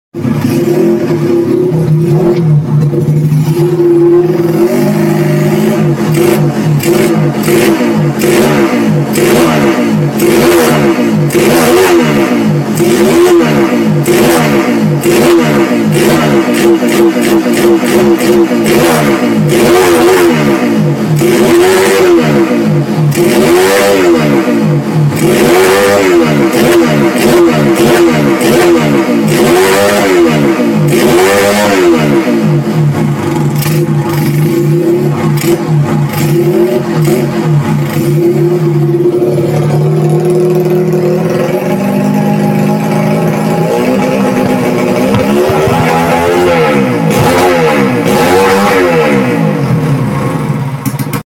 cek sound jupiter z robot sound effects free download